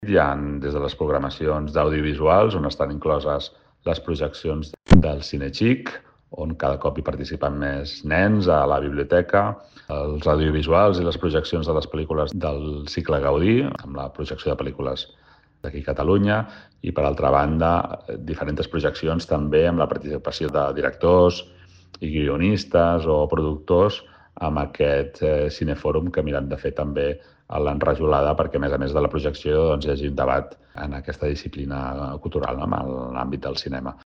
Sergi Corral, regidor de Cultura de l'Ajuntament de Martorell